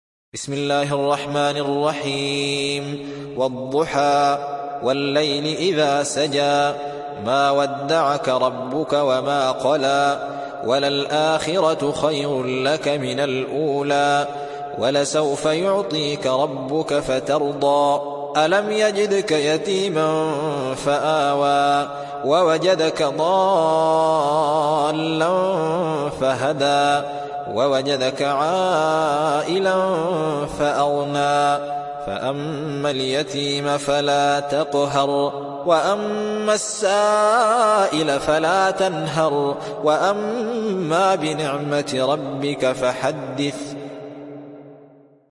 Riwayat Hafs from Asim